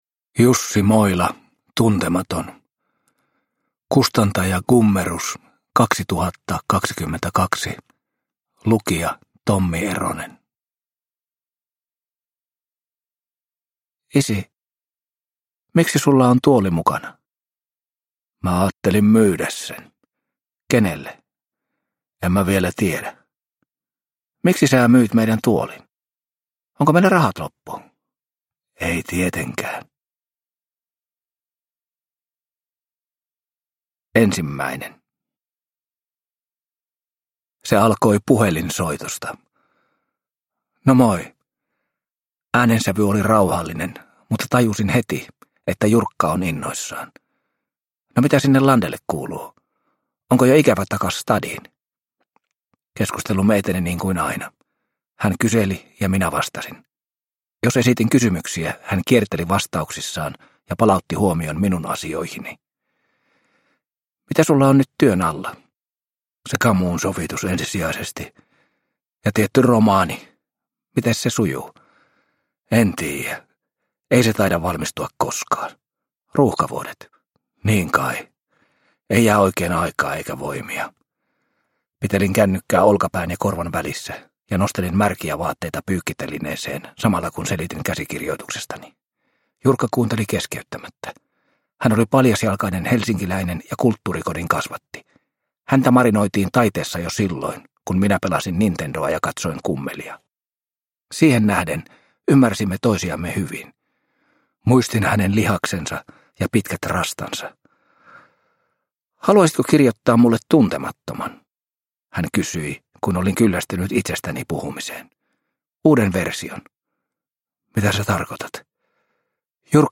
Tuntematon – Ljudbok – Laddas ner